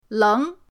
leng2.mp3